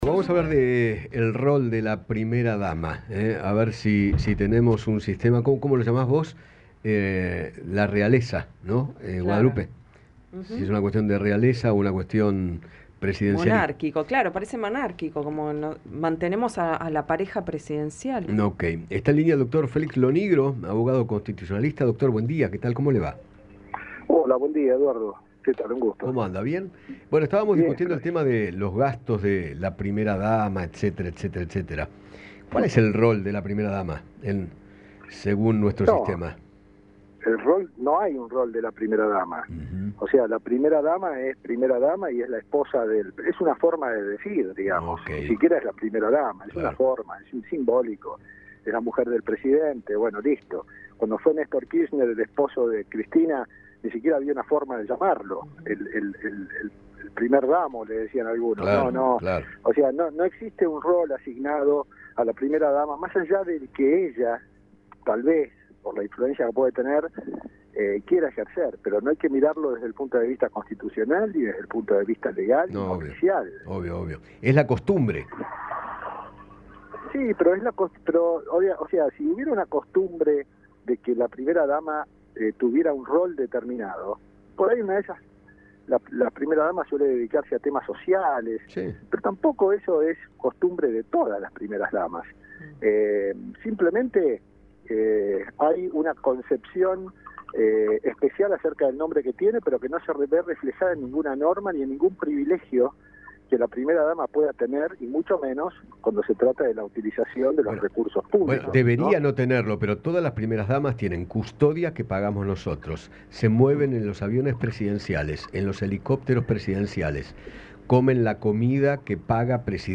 conversó con Eduardo Feinmann sobre el lugar que ocupa la primera dama y  aseguró que “es la mujer del Presidente y listo”.